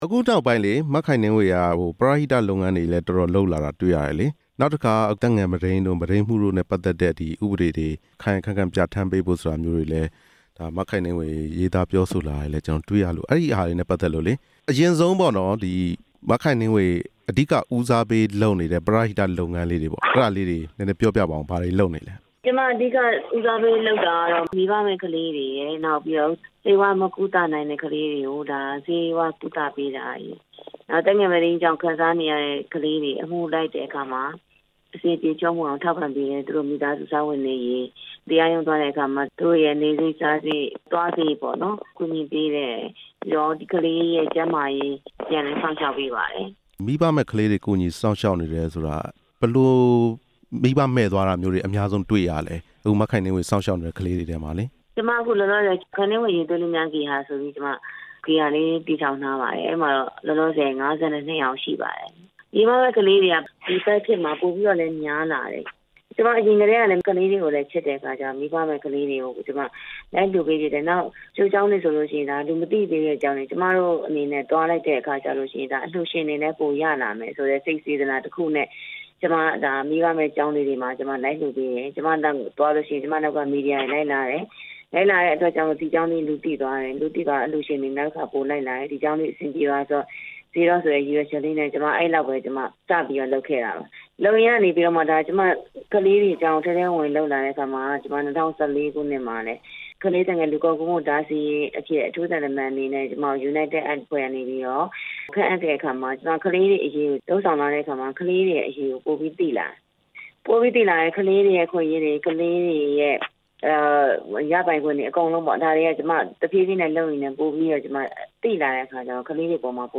သက်ငယ်မုဒိန်းကျူးလွန်ခံ ကလေးငယ်တွေကို ကူညီပေးနေတဲ့ ရုပ်ရှင်မင်းသမီး ခိုင်နှင်းဝေနဲ့ မေးမြန်းချက်